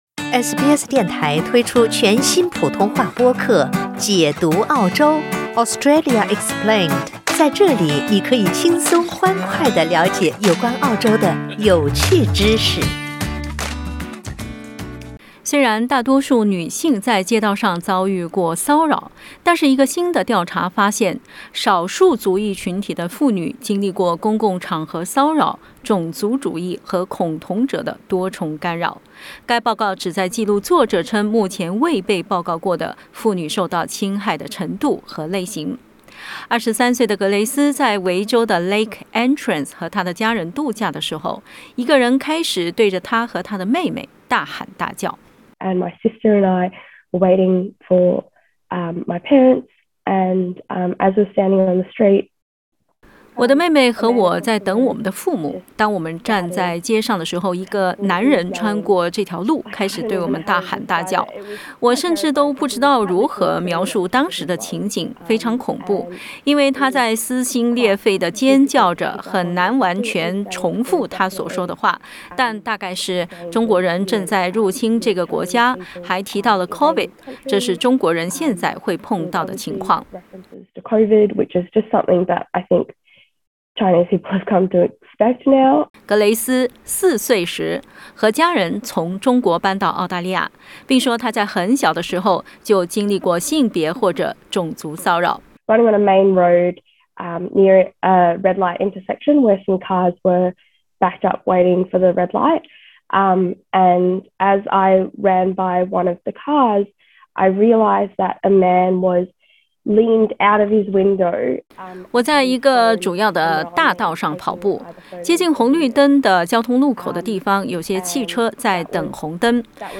（ International Anti-Street Harassment Week ） （請聽報道） 澳大利亞人必鬚與他人保持至少1.5米的社交距離，請查看您所在州或領地的最新社交限制措施。